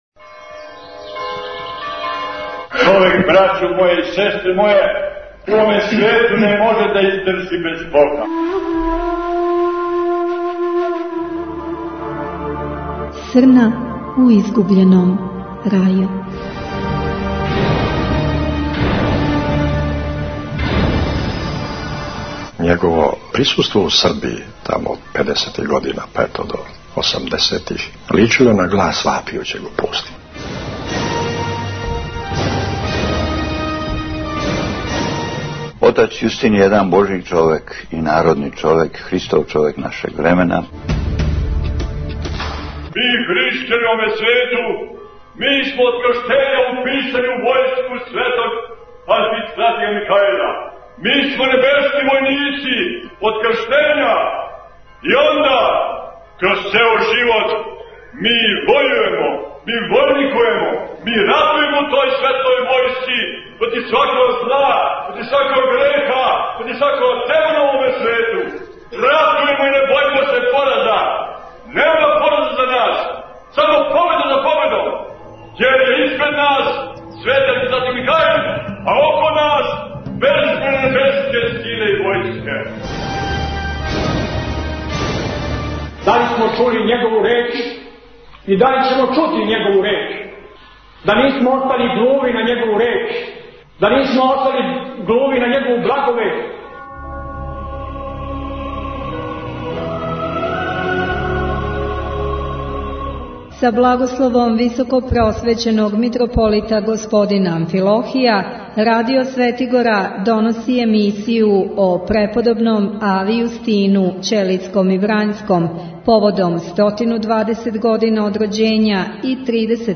У првој из серијала емисија "Срна у изгубљеном рају" имамо велики благослов и радост да нам о свом духовном оцу Преподобном ави Јустину говори Високопреосвећени Митрополит Г. Амфилохије, а са његовим благословом доносимо и, до сад необјављени, текст аве Јустина "Плава птица".